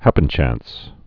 (hăpən-chăns)